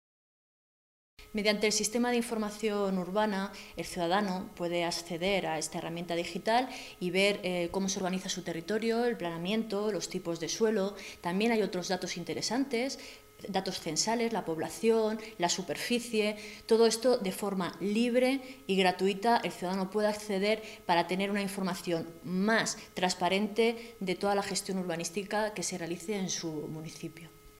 Marta Abarca, diputada regional del PSOE de Castilla-La Mancha
Cortes de audio de la rueda de prensa